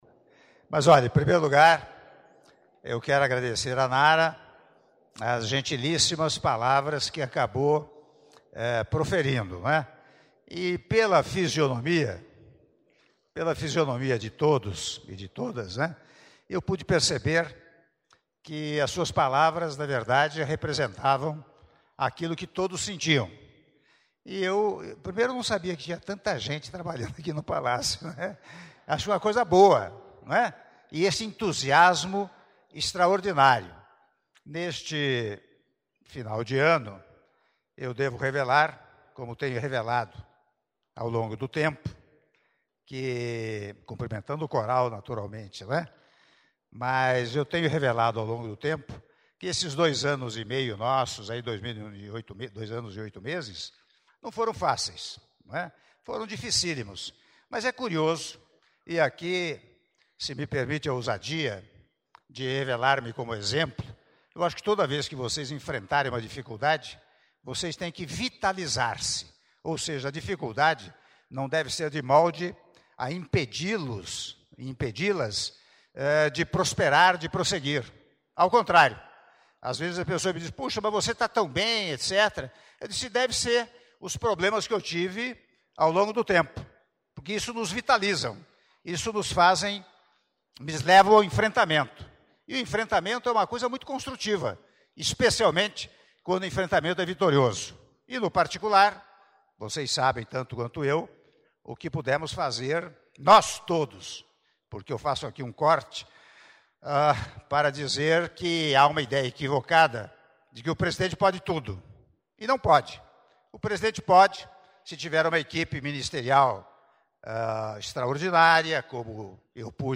Áudio do discurso do Presidente da República, Michel Temer, durante encontro com servidores da Presidência da República - Palácio do Planalto (06min45s)